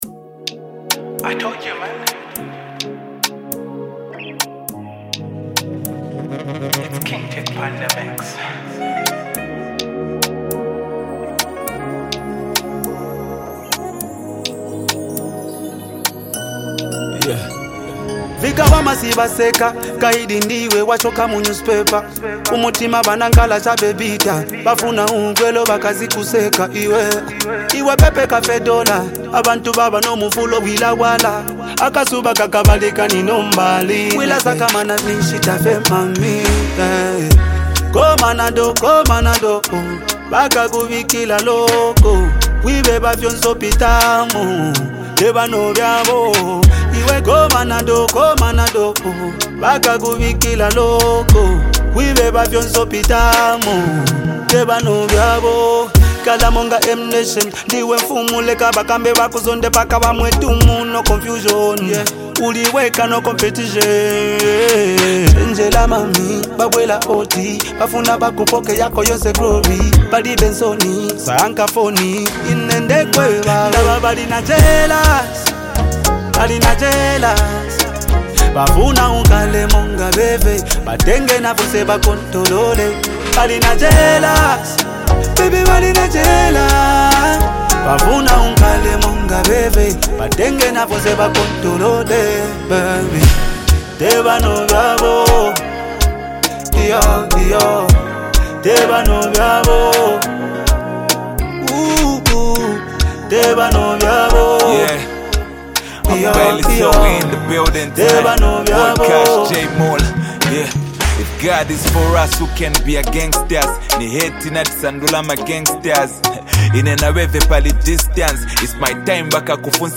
a hard-hitting track
emotional delivery
a perfect balance of deep bass and melodic elements
smooth delivery
sharp flow